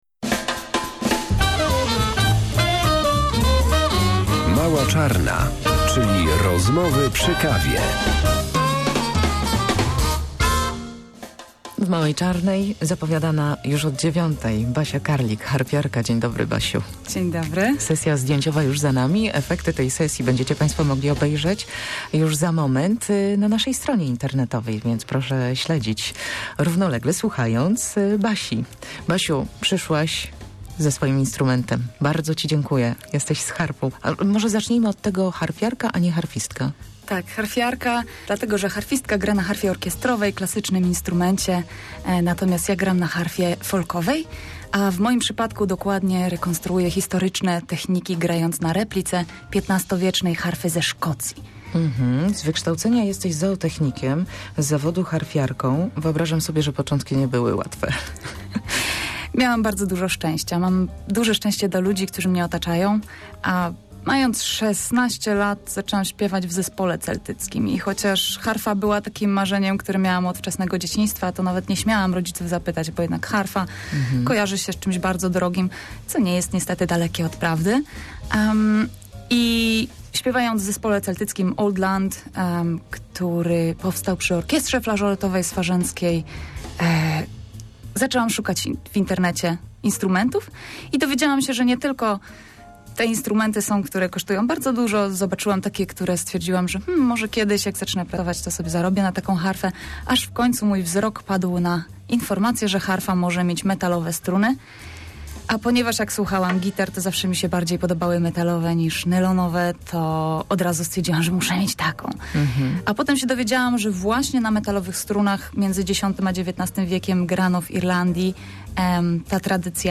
Harfiarze grają za pomocą długich paznokci, uderzając nimi struny i wyciszając je opuszkami palców.
Jej harfa ma metalowe struny.